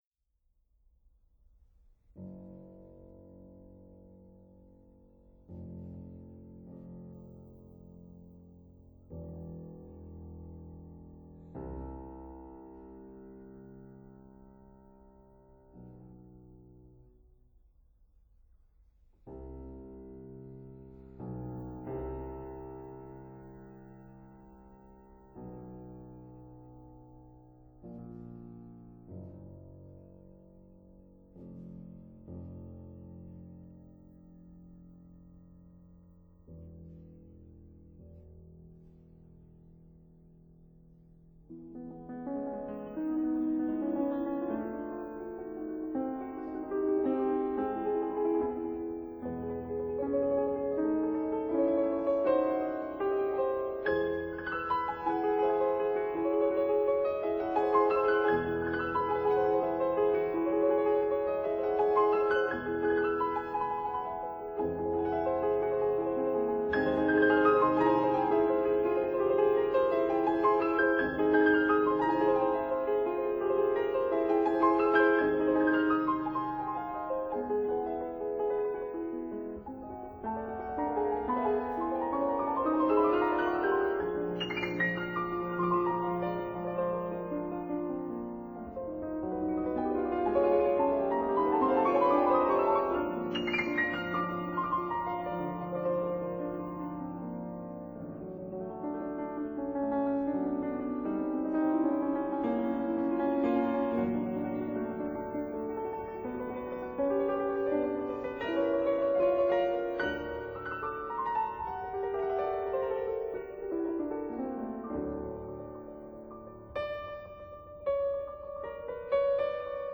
Version for Piano Duo